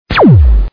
Tiếng Súng Laser bắn trong phim khoa học viễn tưởng